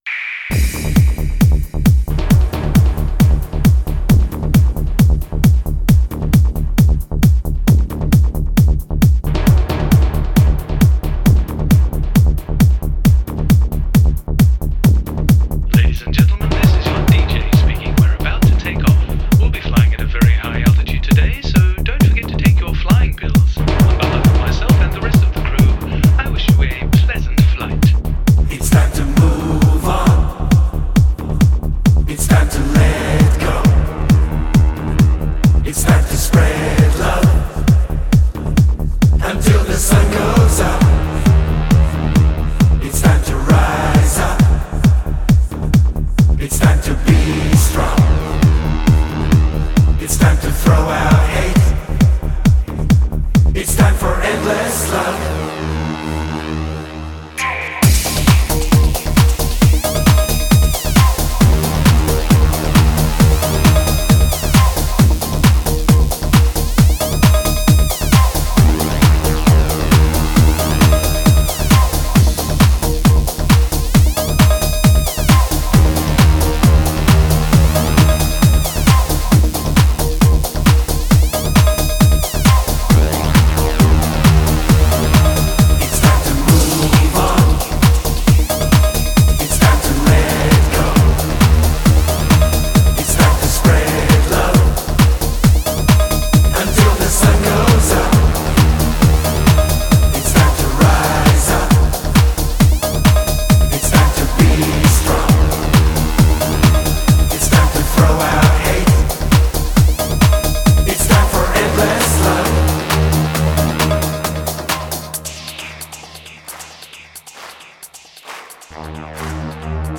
DJ舞曲，都是快节奏、劲爆的音乐。